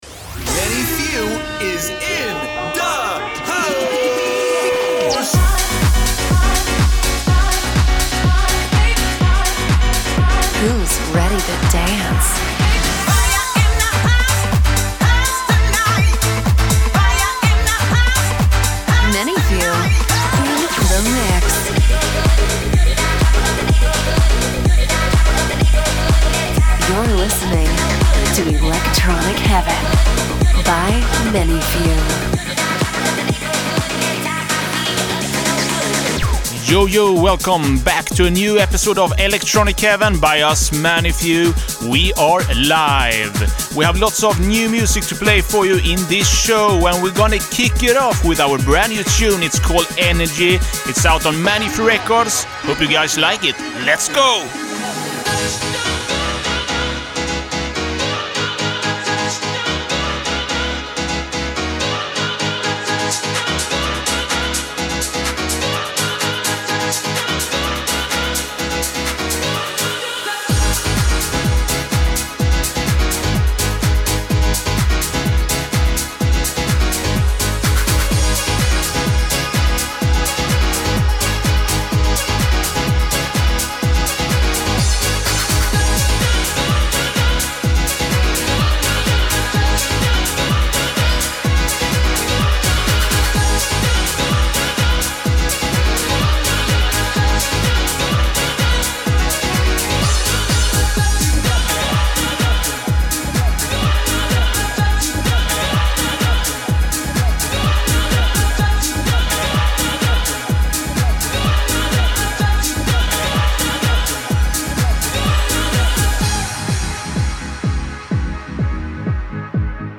includes exclusive remixes, edits and unreleased tracks